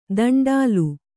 ♪ danḍālu